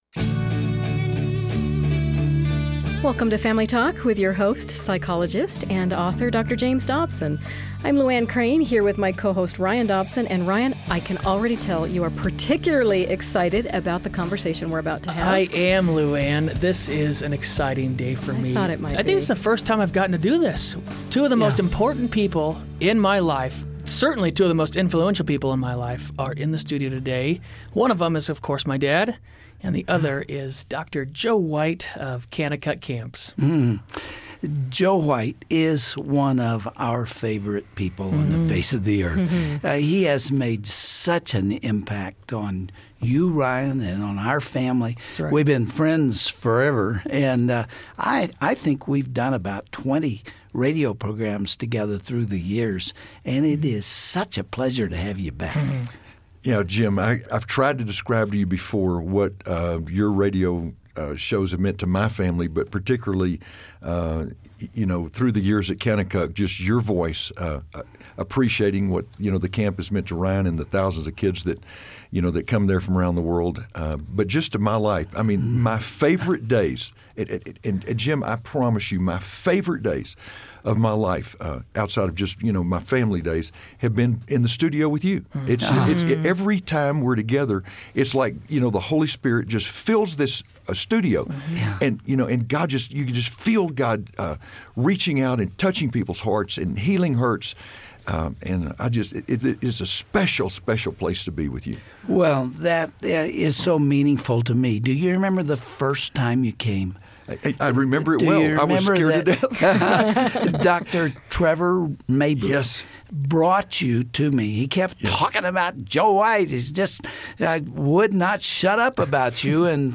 How can this simple , yet profound , act of placing Christ as the center and foundation of your marriage assure true happiness and grow your relationship into one that lasts a lifetime? Listen in on the conversation here!